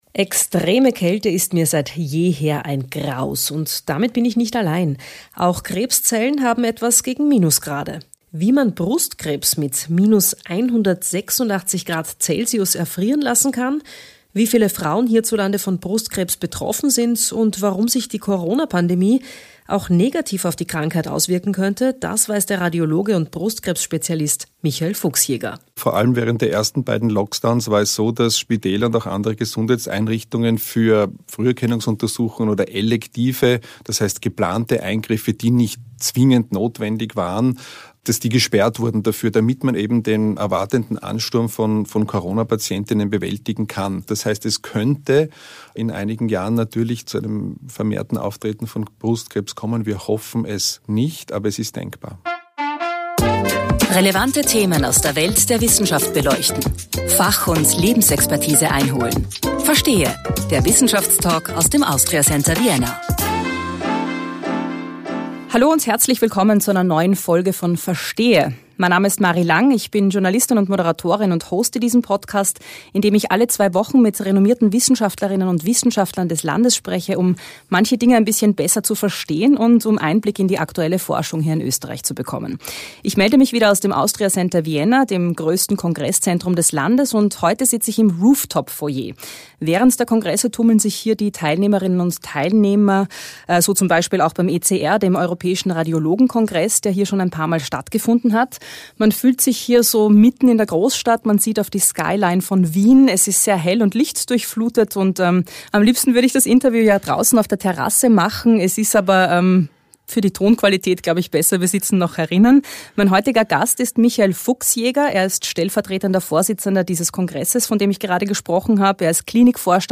Der Wissenschaftstalk aus dem Austria Center Vienna Podcast